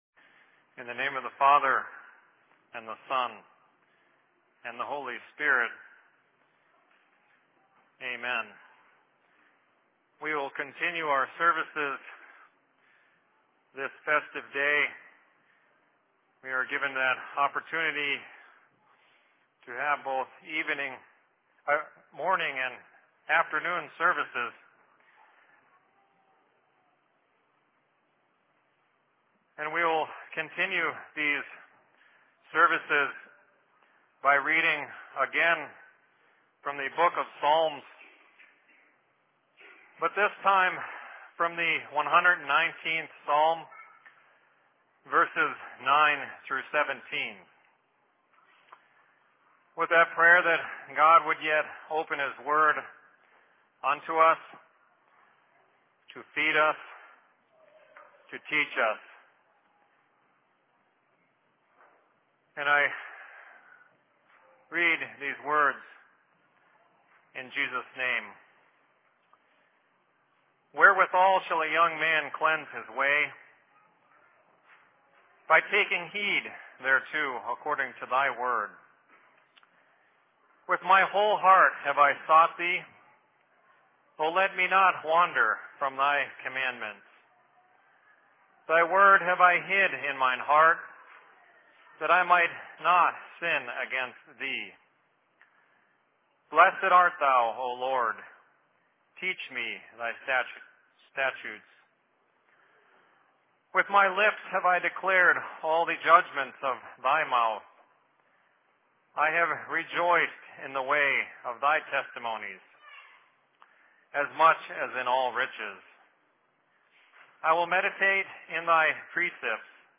Sermon in Seattle 09.09.2012
Location: LLC Seattle